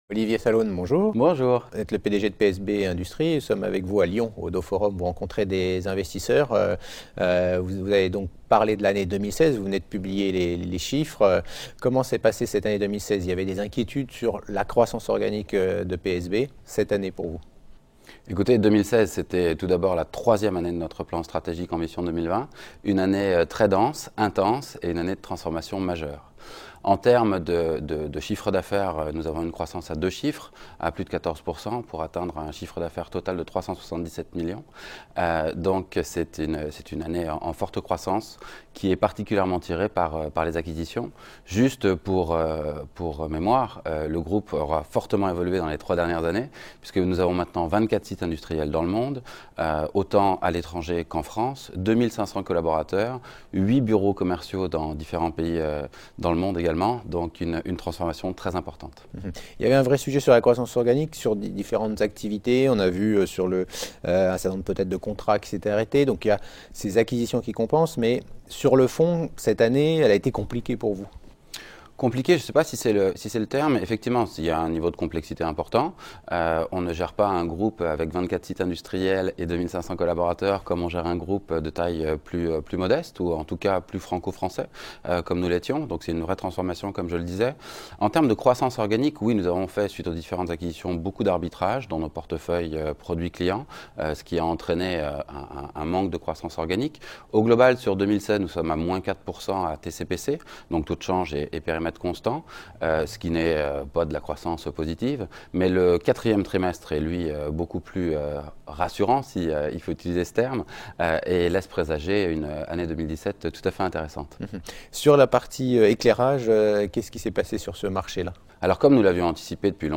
Rencontres avec les dirigeants au Oddo Forum de Lyon du 5 et 6 janvier 2017
La Web Tv a rencontré les dirigeants d’entreprises cotées au Oddo Forum de Lyon du 5 et 6 janvier 2017.